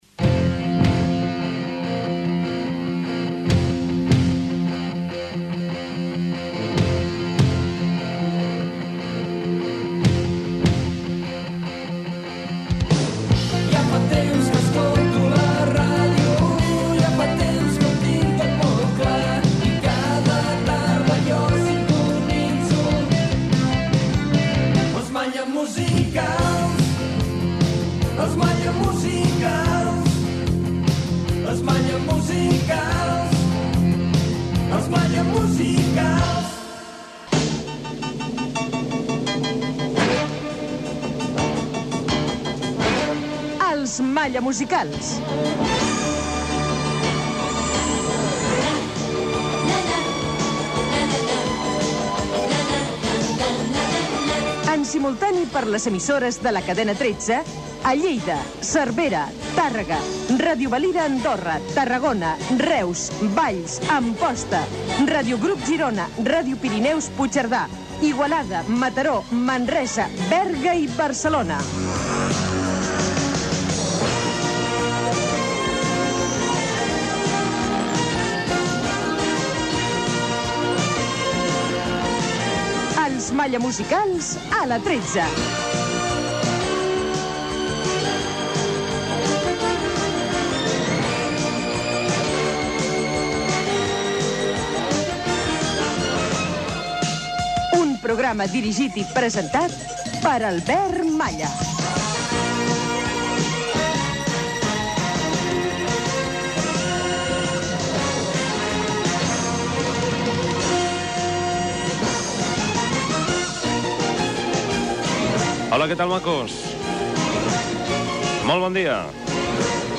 Sintonia del programa,emissores de la Cadena 13 que emeten el programa, presentació des dels nous estudis de la Cadena 13 a Barcelona
Gènere radiofònic Musical